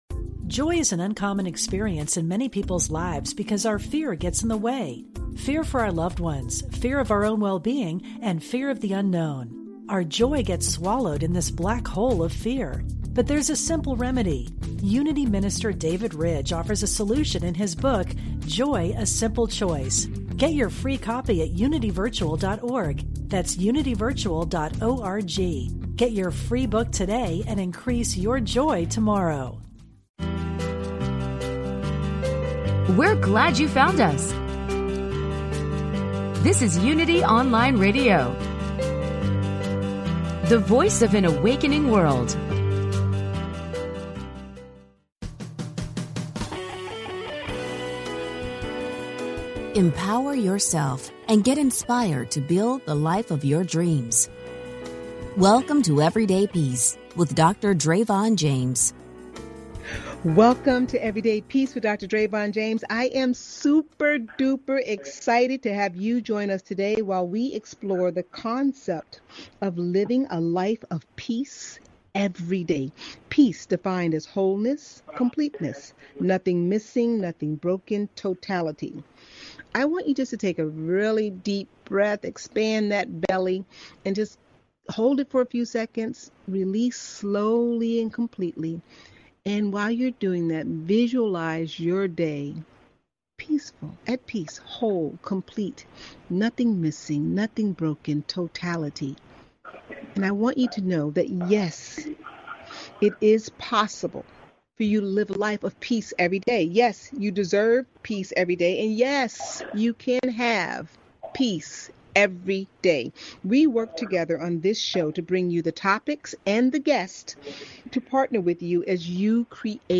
Talk Show Episode
Please enjoy this encore broadcast from November 2, 2020.